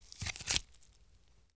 Flesh Paddle Hits Body Sound
horror